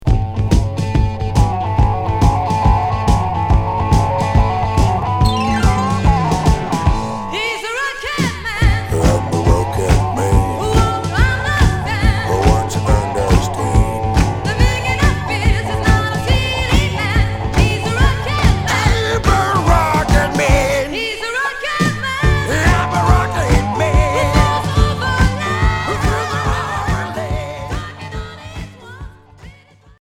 Glitter rock Unique 45t retour à l'accueil